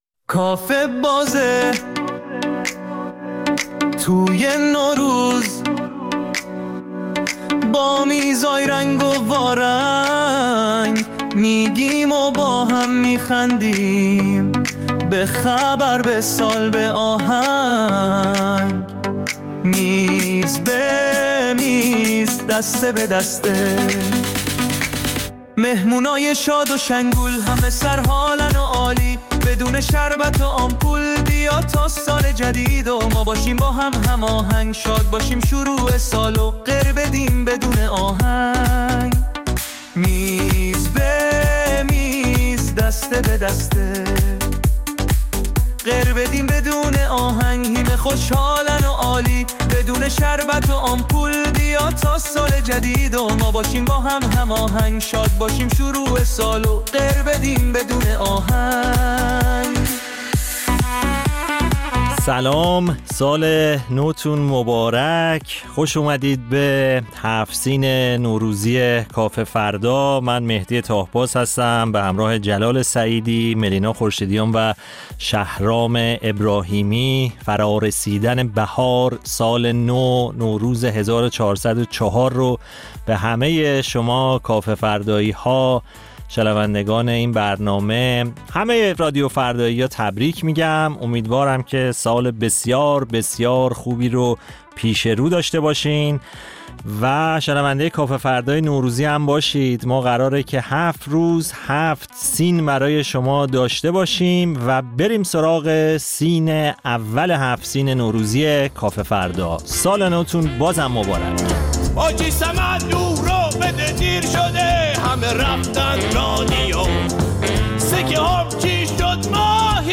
در کافه فردای نوروز با سفره هفت‌سین خاص کافه‌فردایی‌ها شوخی می‌کنیم و در میز‌های مختلف و بخش‌های متفاوت نمایشی وجوه متفاوت نوروز و تعطیلات نوروزی را در کنار هم به مرور می‌نشینیم.